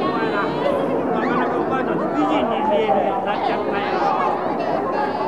これを見てみると、居酒屋とキャバレーはほぼ同じであり、300Hzより高い音に関しては周波数=fが高くなるに従い環境音のパワー密度が低下していることがわかる。しかもその低下の仕方は滑らかである。
なかなかこれが雰囲気のある音なので、これで良いのである。
kyabares.wav